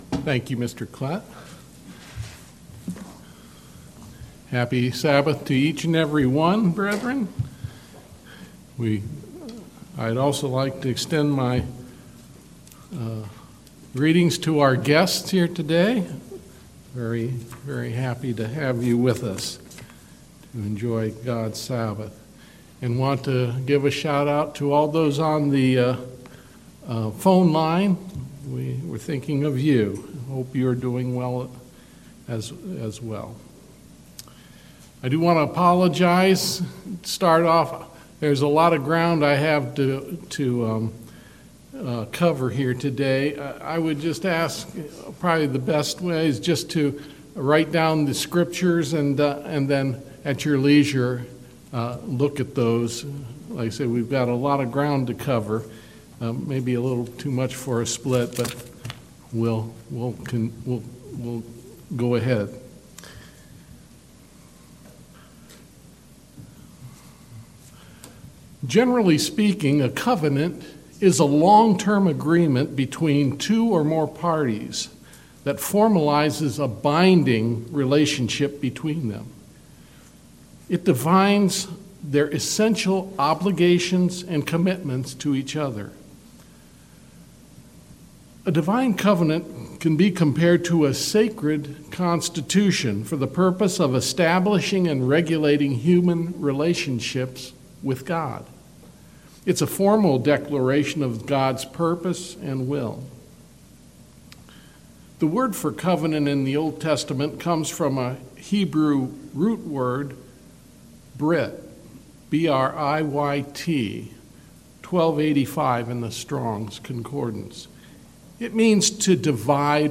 Given in Mansfield, OH